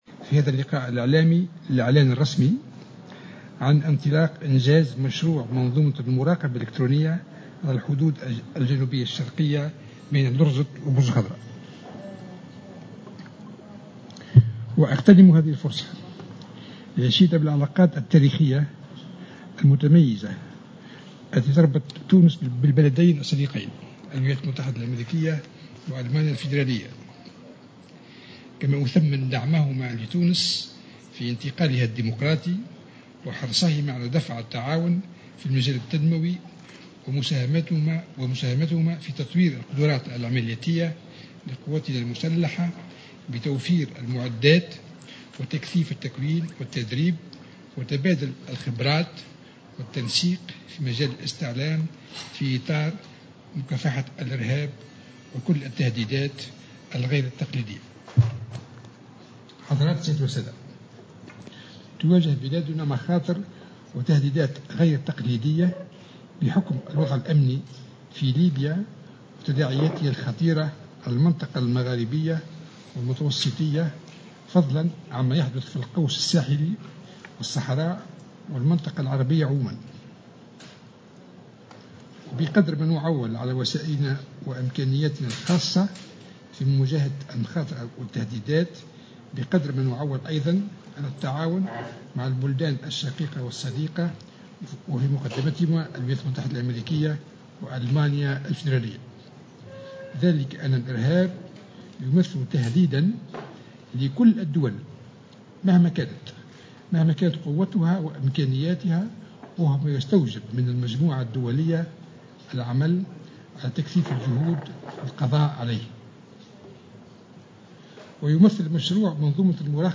أعلن اليوم وزير الدفاع الوطني فرحات الحرشاني في ندوة صحفية مشتركة مع سفير ألمانيا الفدرالية في تونس أندرياس لينيك وسفير أمريكا بتونس دنيال روبرتسون، عن الانطلاق الرسمي لانجاز مشروع منظومة المراقبة الالكترونية على الحدود الجنوبية الشرقية بين لزرط وبرج الخضراء، الذي ستنتهي أشغاله في جويلية 2018 على أقصى تقدير.